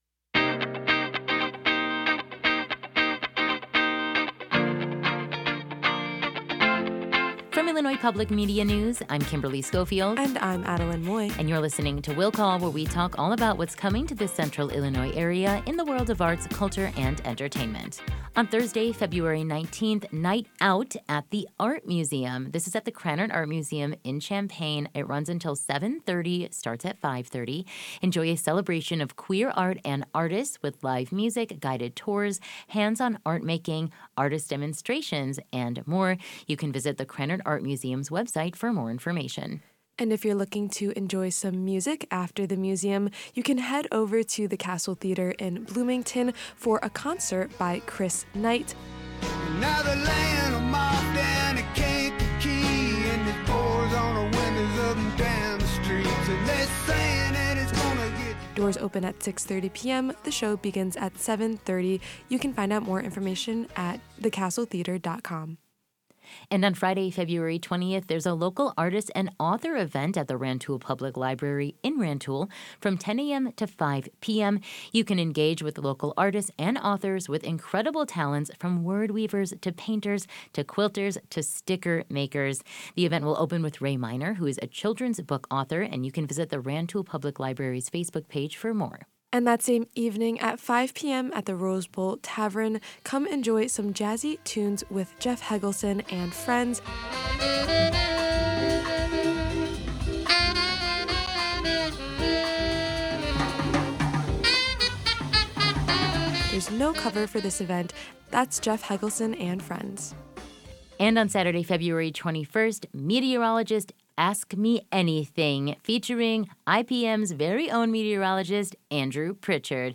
talk about weekend events on IPM News AM 580 and FM 90.9